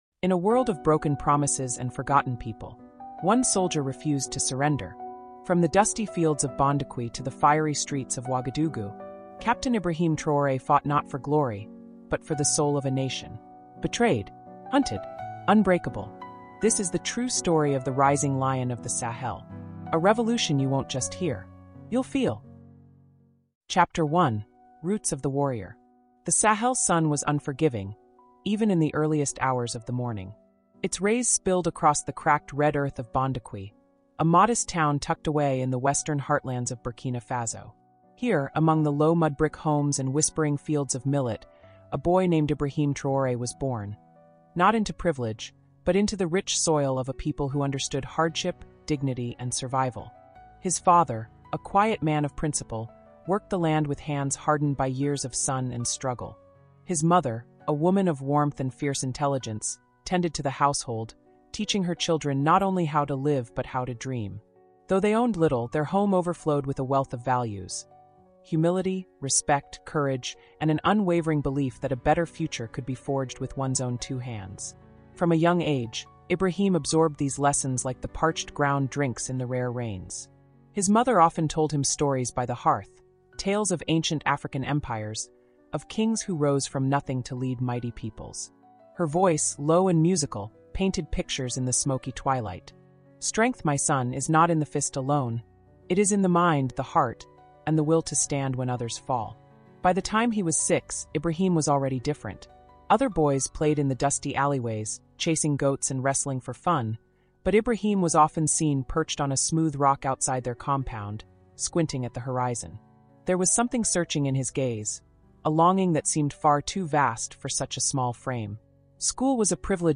This audiobook delves into the formative moments that forged a revolutionary leader committed to his people.